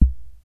07_Kick_14_SP.wav